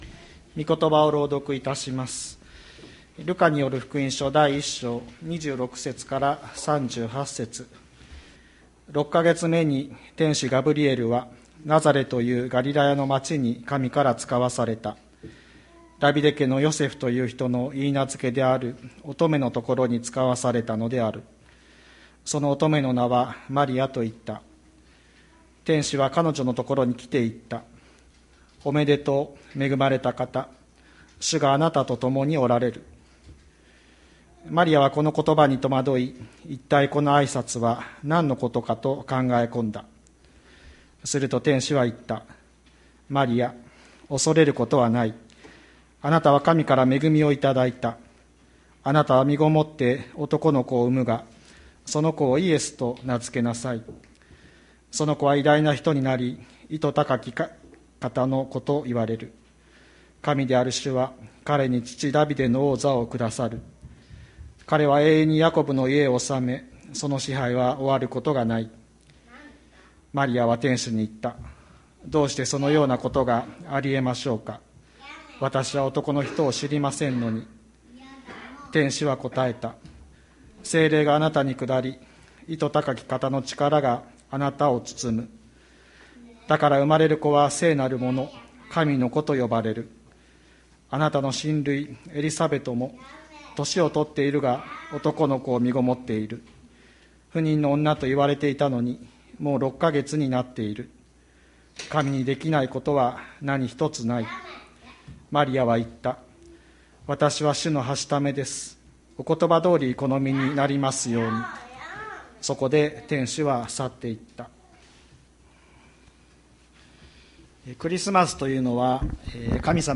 2020年12月13日朝の礼拝「神の不思議なご計画」吹田市千里山のキリスト教会
千里山教会 2020年12月13日の礼拝メッセージ。